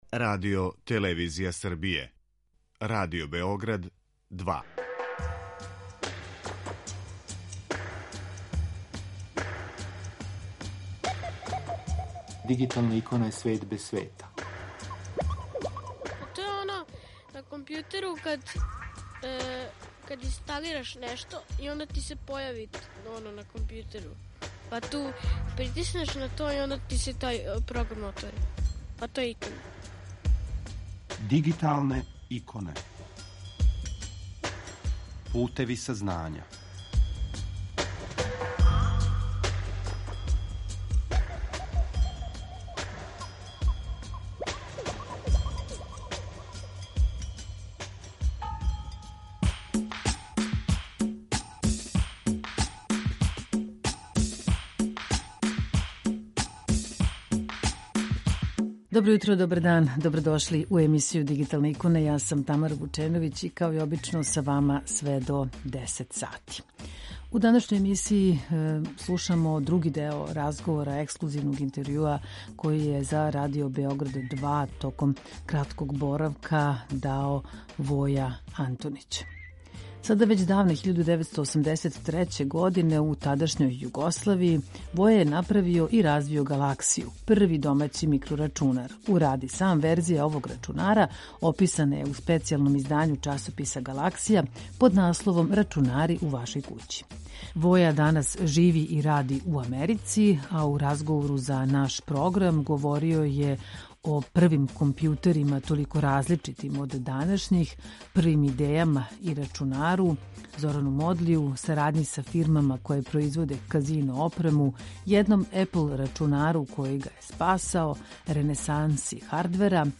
У данашњој емисији Дигиталне иконе слушамо други део разговора са једном правом дигиталном иконом